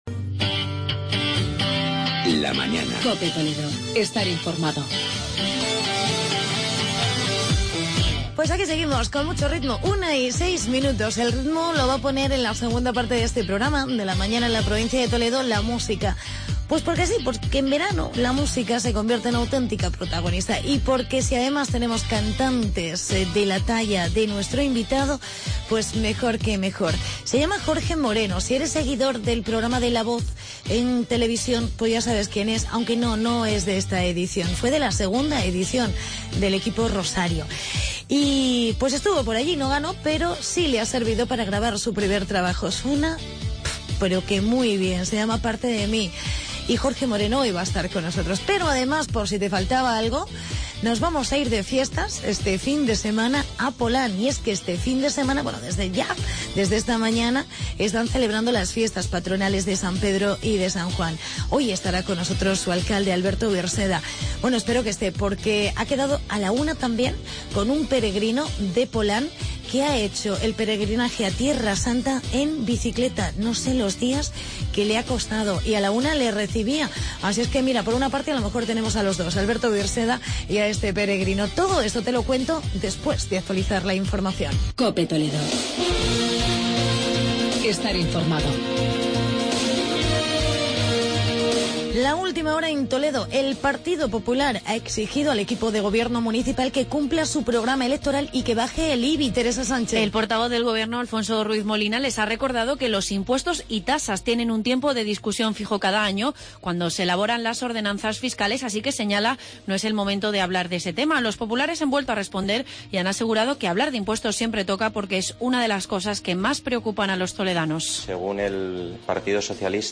Entrevista alcalde de Polán